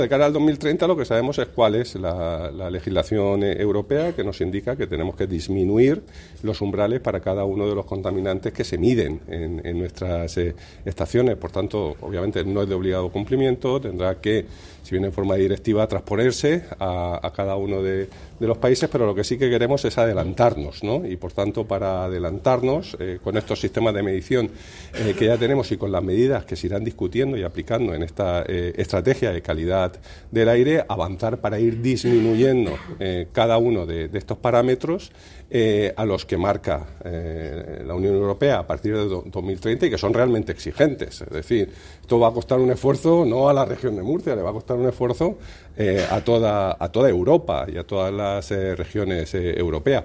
El consejero de Medio Ambiente, Universidades, Investigación y Mar Menor, Juan María Vázquez, profundiza sobre las medidas para que la Región se adelante al cambio de legislación europea que entrará en vigor en 2030.
Así lo afirmó hoy el consejero de Medio Ambiente, Universidades, Investigación y Mar Menor, Juan María Vázquez, durante la apertura de las II Jornadas Sobre la Calidad del Aire en la Región de Murcia, el foro de expertos en el que se presentó el borrador del documento de la Estrategia de Calidad del Aire para la Región de Murcia-Horizonte 2030.